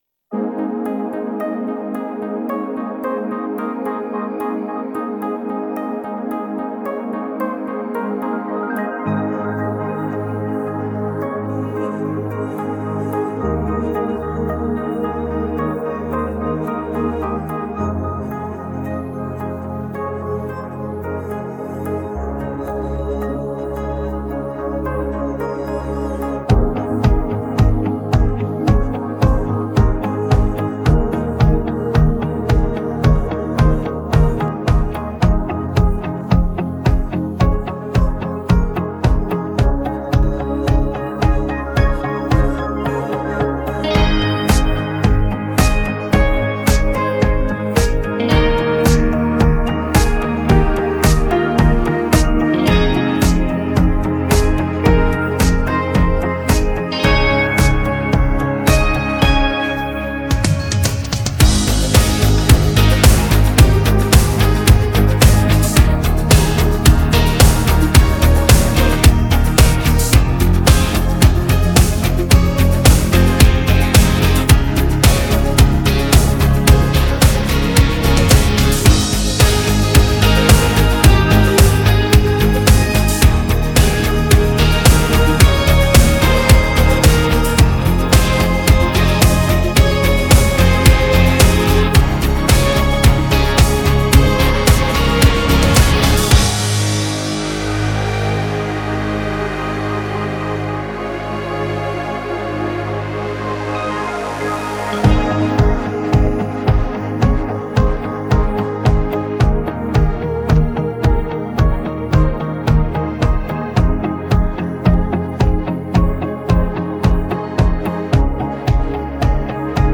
Качественный минус